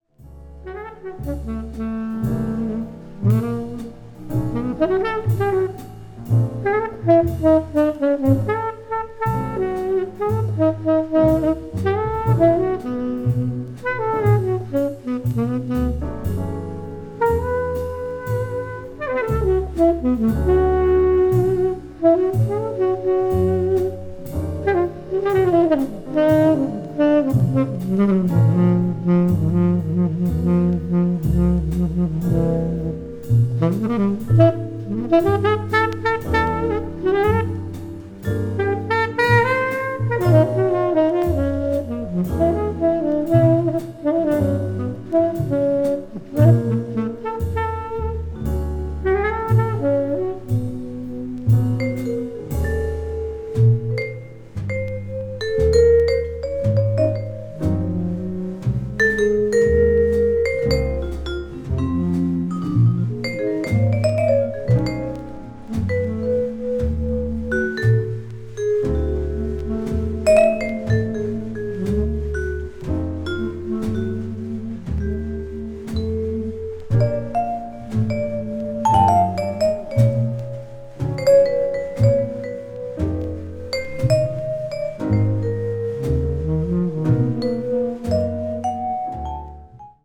media : EX-/EX-(薄いスリキズによるわずかなチリノイズ/一部軽いチリノイズが入る箇所あり)
bop   modern jazz   mood jazz